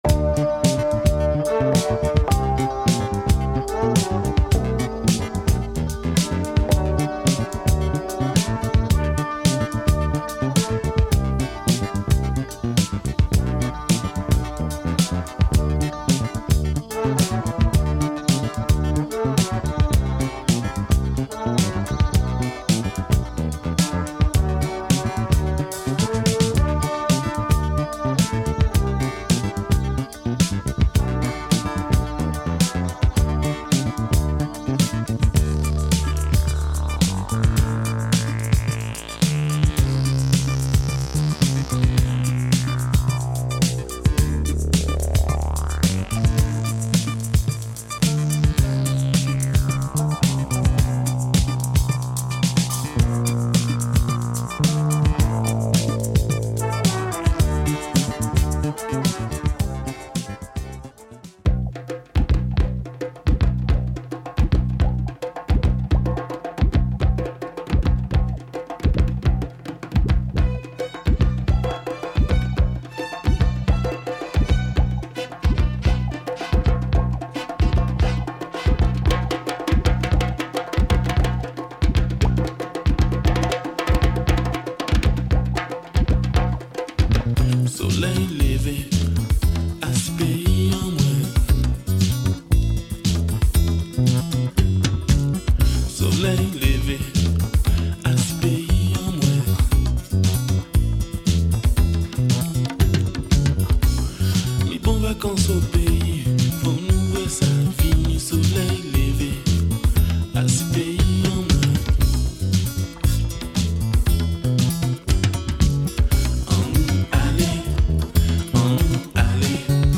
Super killer electro zouk funk
bass
percussions, and lots of keyboards !